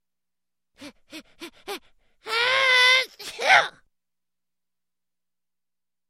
Здесь вы можете скачать или послушать онлайн странные шумы, шаги и другие аудиофрагменты.
Звук домашнего хранителя, кашляющего от пыли